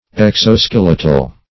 Search Result for " exoskeletal" : The Collaborative International Dictionary of English v.0.48: Exoskeletal \Ex`o*skel"e*tal\, a. (Anat.) Pertaining to the exoskeleton; as exoskeletal muscles.